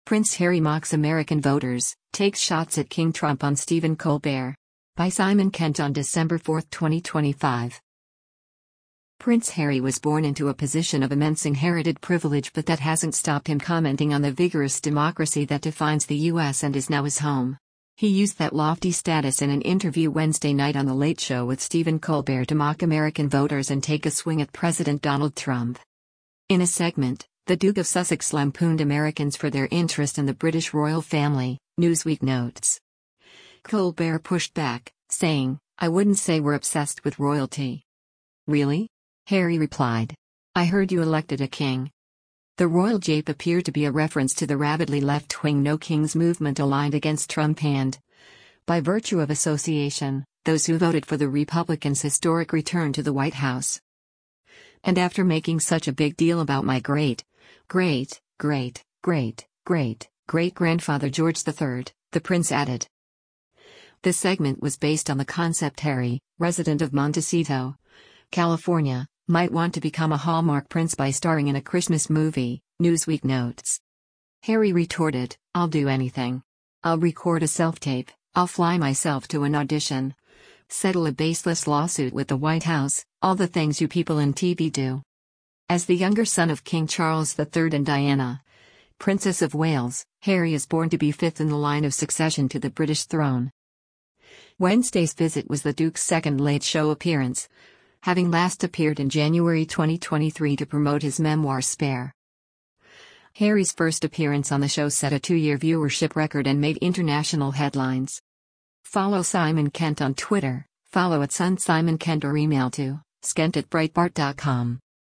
He used that lofty status in an interview Wednesday night on The Late Show with Stephen Colbert to mock American voters and take a swing at President Donald Trump.